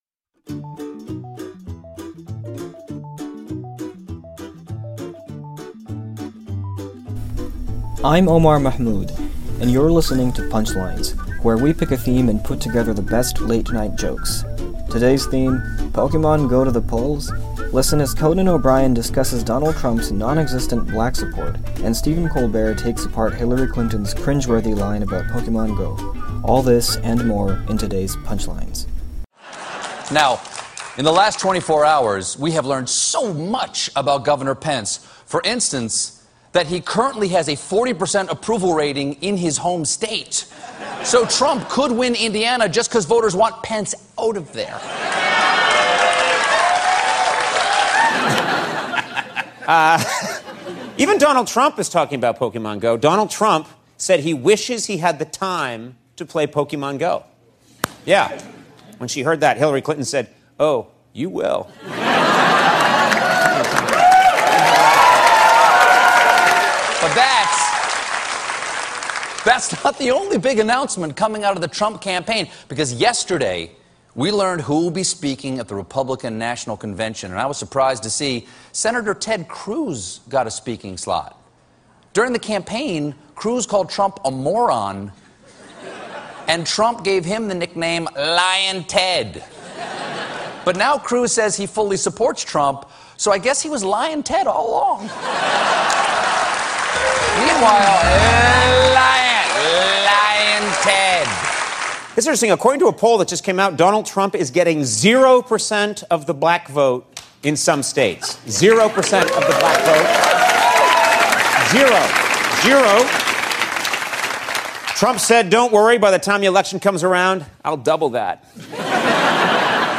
The late-night comics on all things politics (and Pokémon).